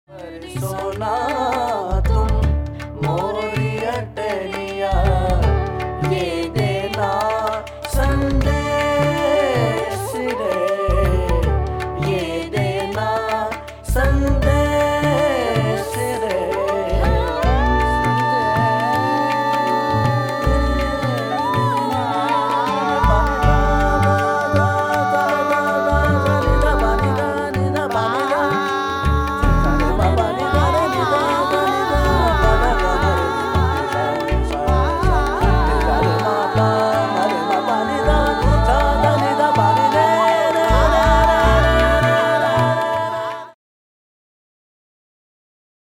Recorded at Lahore Folk Studio Dec '05